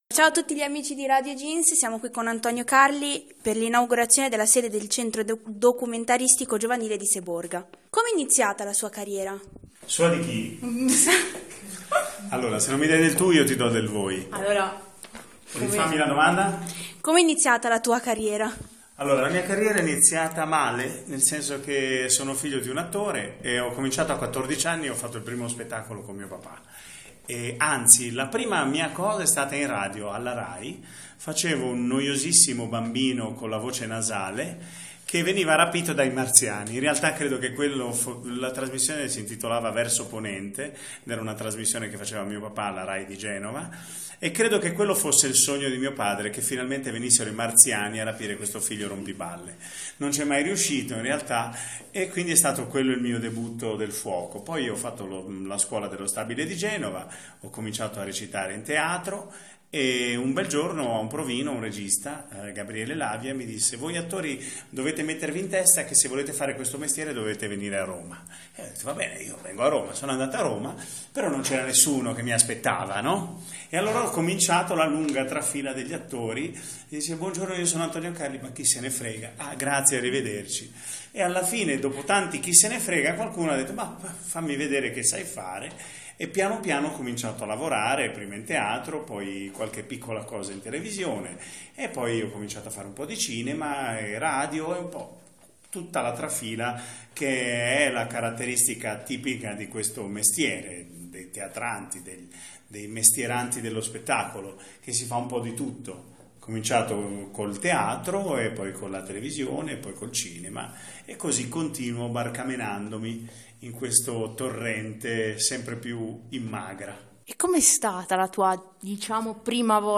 Intervista
in occasione dell'inagurazione del "centro Documentaristico" di Seborga